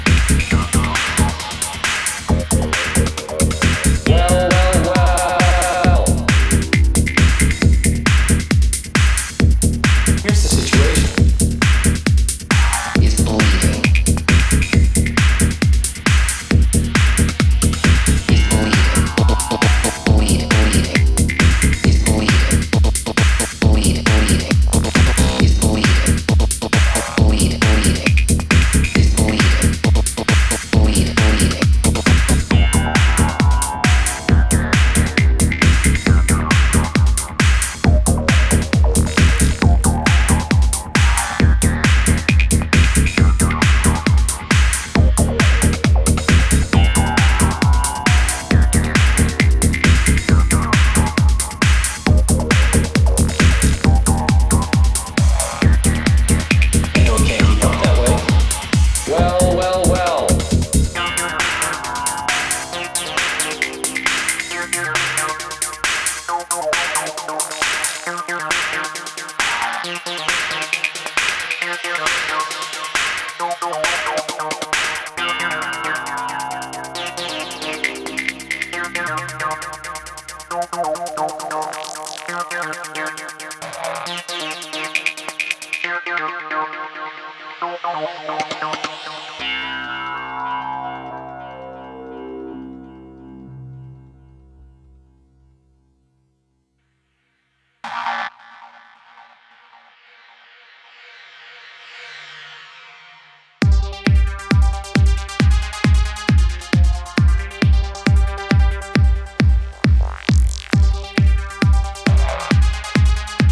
New Beat and Proto House
injecting a fresh, forward-thinking aesthetic into the EP.